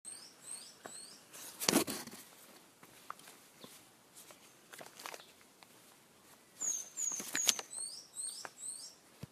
Chestnut-headed Tanager (Thlypopsis pyrrhocoma)
Canto-2.mp3
Life Stage: Adult
Province / Department: Misiones
Location or protected area: Campo Ramón
Condition: Wild
Certainty: Recorded vocal